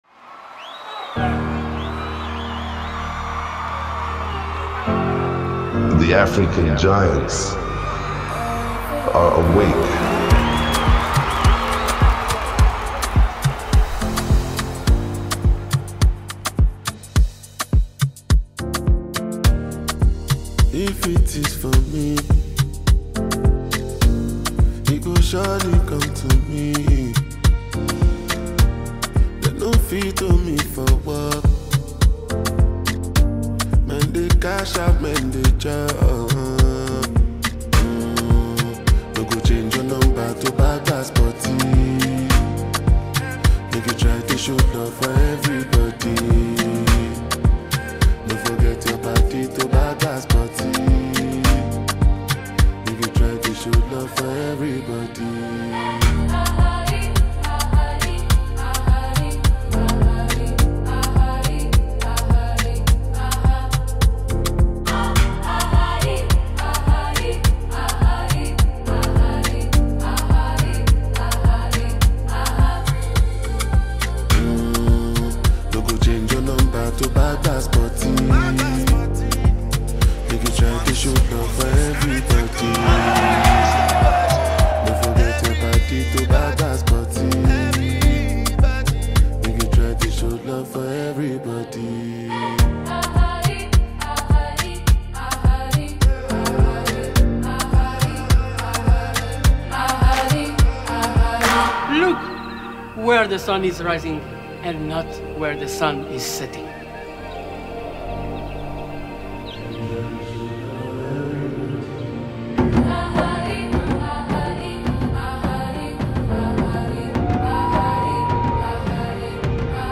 Latest Nigeria Afro-Beats Single (2026)
Genre: Afro-Beats